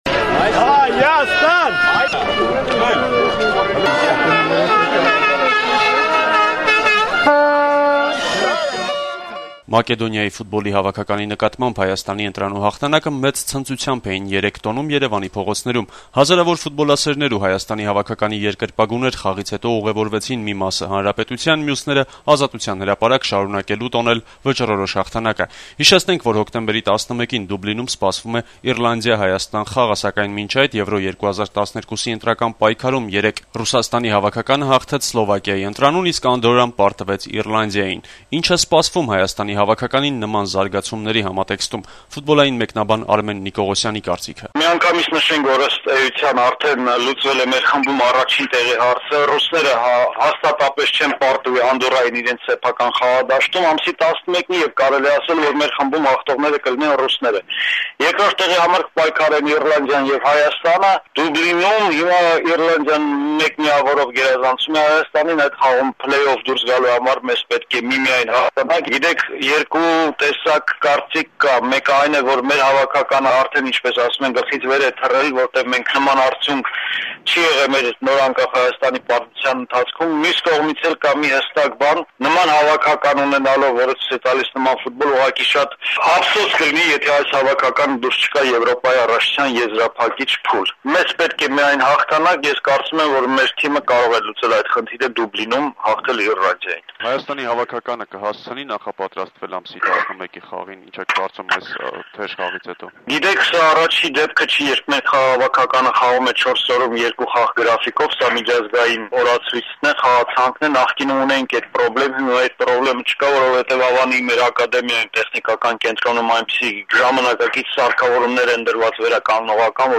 «Ազատություն» ռադիոկայանի հետ զրույցում
ֆուբոլային մեկնաբան